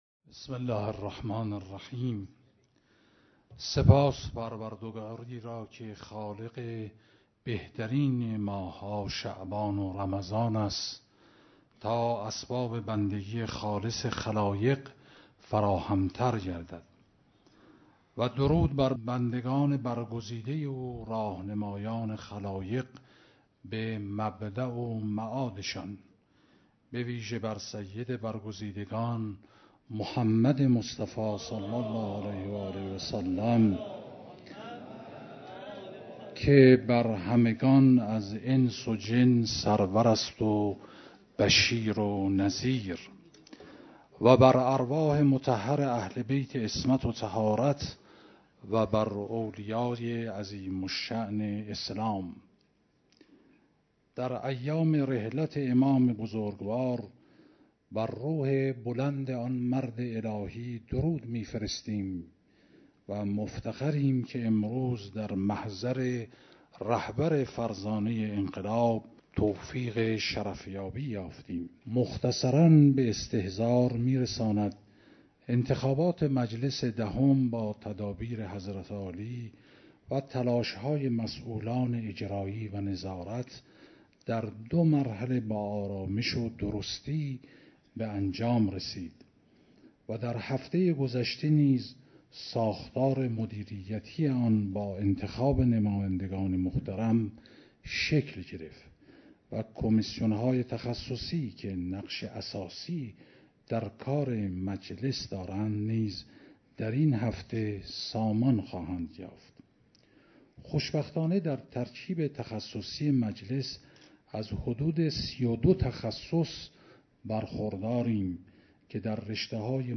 ارائه گزارش جناب آقای دکتر لاریجانی رئیس مجلس شورای اسلامی